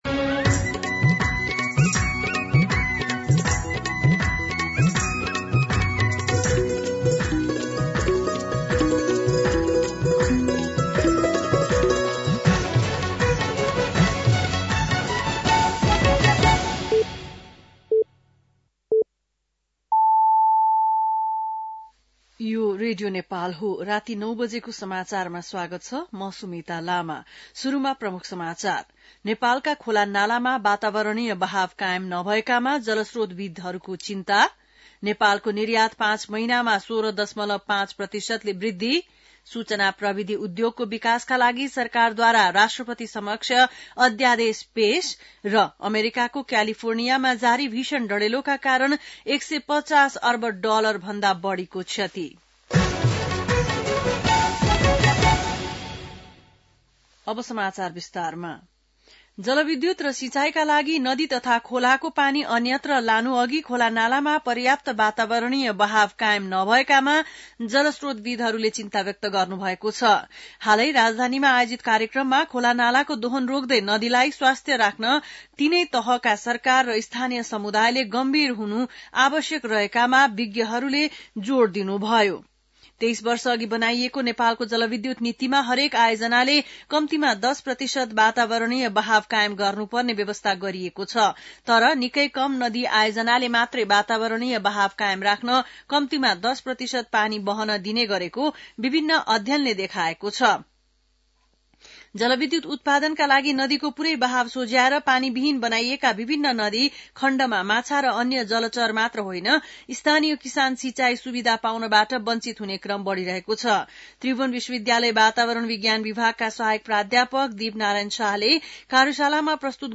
बेलुकी ९ बजेको नेपाली समाचार : २८ पुष , २०८१
9-PM-Nepali-News-.mp3